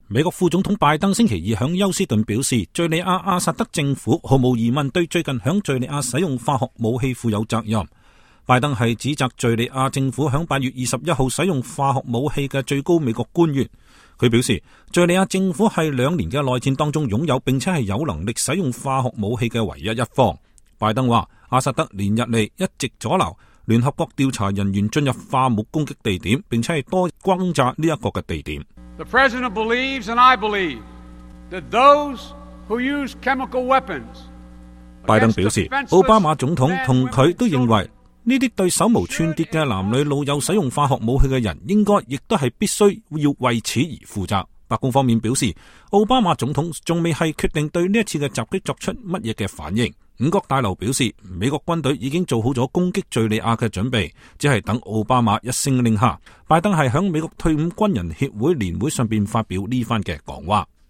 拜登是在美國退伍軍人協會年會上發表這番講話的。